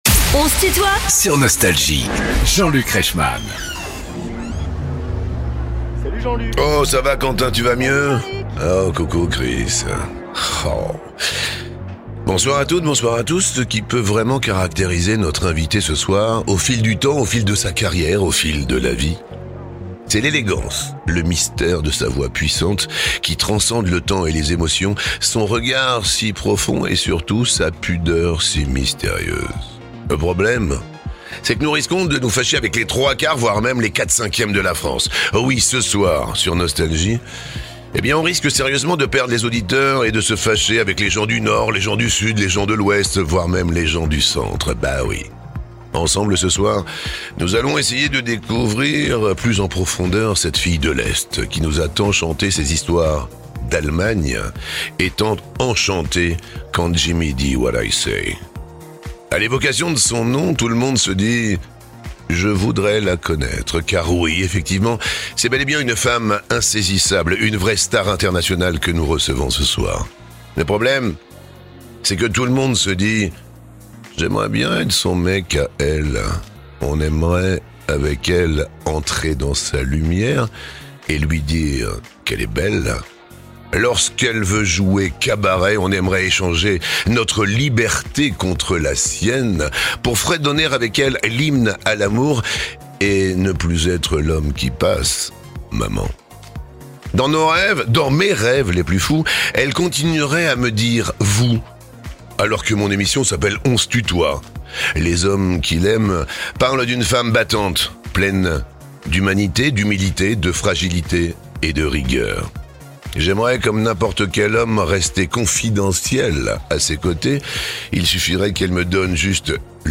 Invitée de "On se tutoie ?..." avec Jean-Luc Reichmann, Patricia Kaas revient sur les moments forts de son incroyable carrière
Les plus grands artistes sont en interview sur Nostalgie.